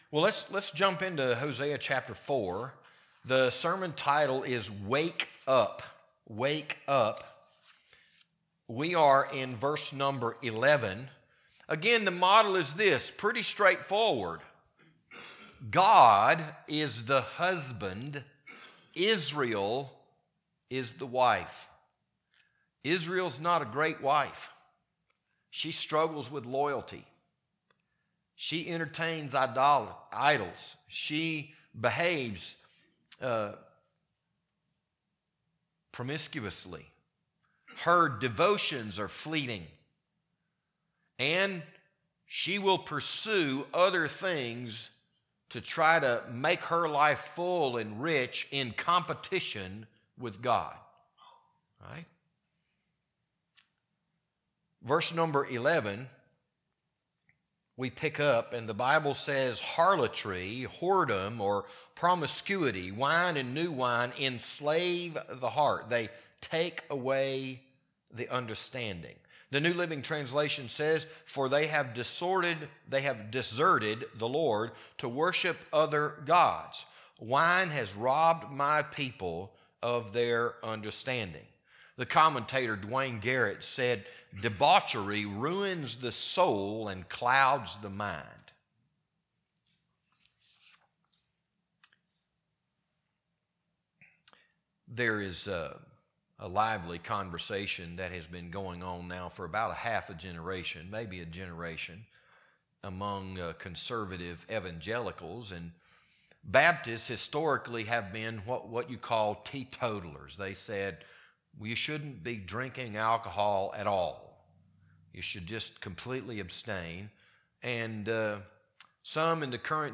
This Sunday evening sermon was recorded on April 6th, 2025.